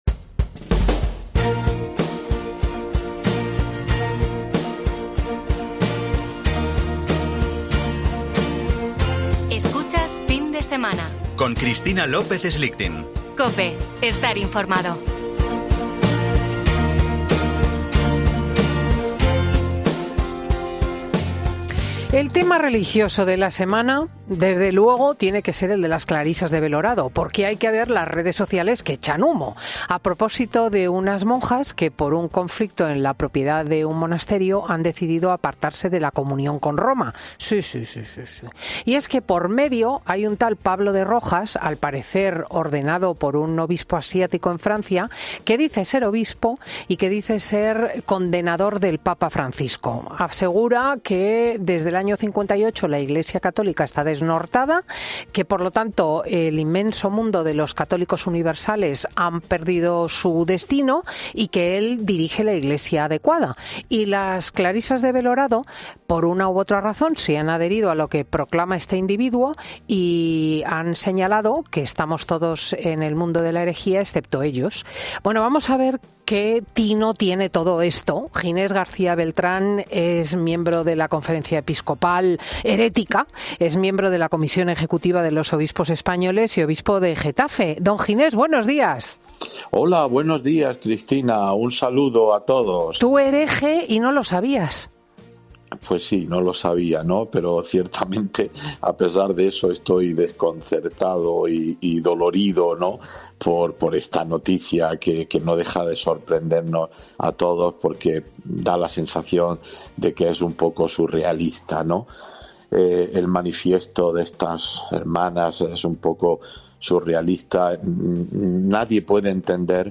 Monseñor Ginés García Beltrán explica la situación en un convento burgalés